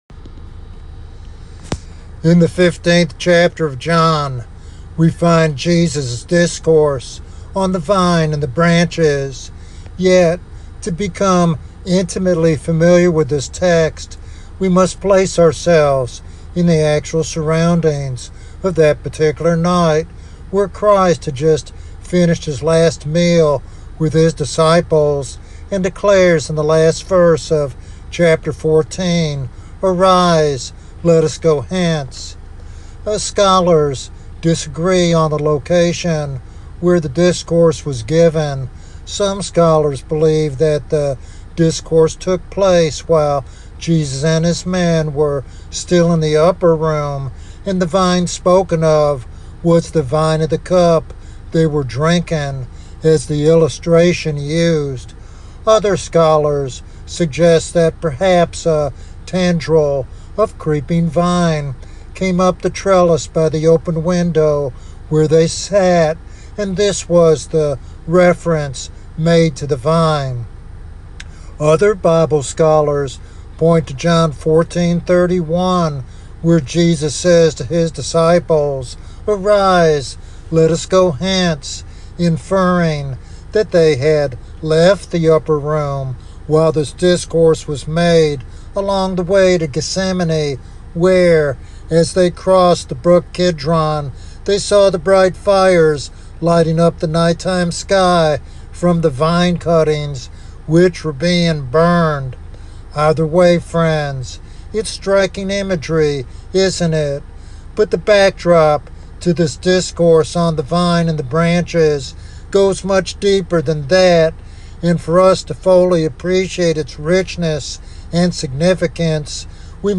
The sermon calls believers to remain steadfast in their relationship with Christ, especially amid persecution, drawing strength from the Holy Spirit to bear lasting fruit for God's glory.